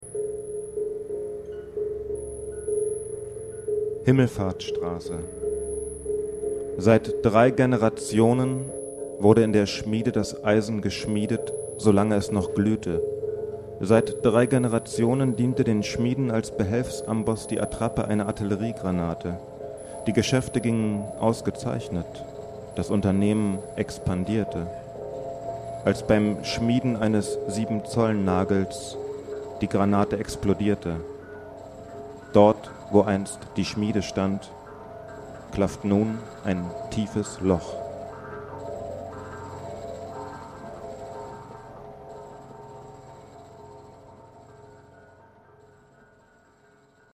(Die Tonaufnahmen sind während einer Lesung anläßlich der Eröffnungstage des Wolfgang Koeppen Literatur-Hauses in Greifswald mitgeschnitten worden.